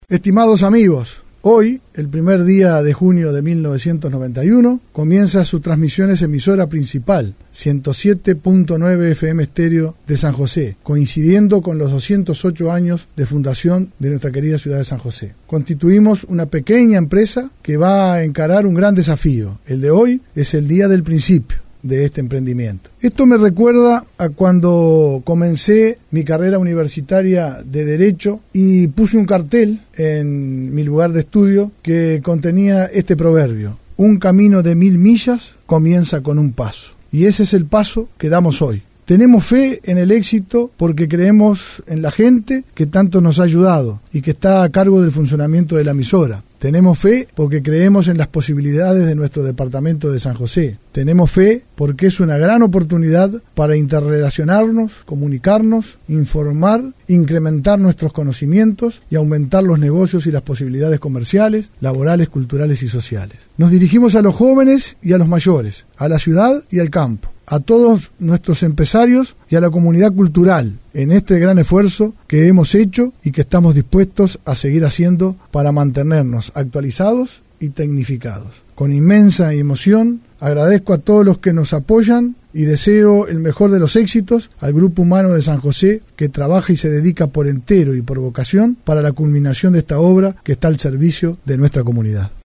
Discurso inaugural
discurso-inaugural.mp3